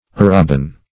Meaning of arabin. arabin synonyms, pronunciation, spelling and more from Free Dictionary.
Search Result for " arabin" : The Collaborative International Dictionary of English v.0.48: Arabin \Ar"a*bin\, n. 1.